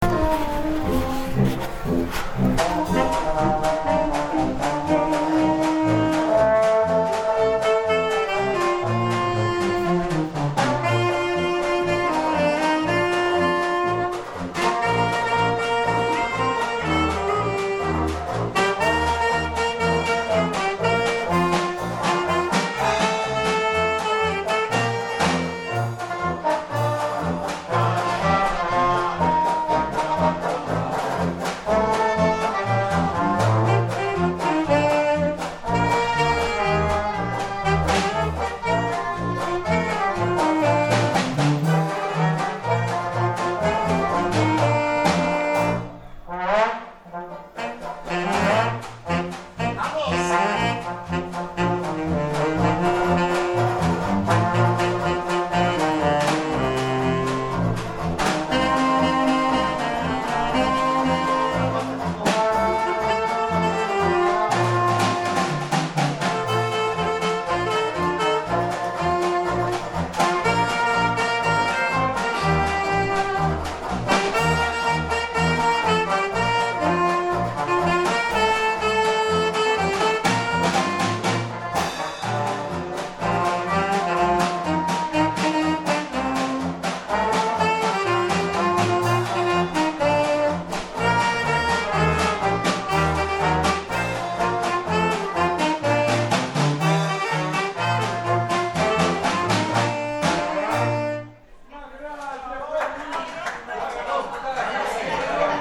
Tornando nel borgo da sogno, dopo la presenza di un gruppo di ottoni che tutto erano fuorché ‘animati’, squadra che si eviterà d’identificare perché tutti hanno il diritto di sfogarsi, l’aggiunta di un organetto ed altri strumenti che se si fosse trattato di parlare di Capodanno quand’era presente la tradizione delle maidunate, tali percussioni sarebbero state definite ‘Bassa Musica’, tal connubio ha fatto sì che alle nove e mezza di sera già salisse il livello di… ‘allegria’!
Tavolata-Al-Centro-Storico-Mega-Pasta-E-Fagioli-Mez-A-Chiazze-del-25-ago.mp3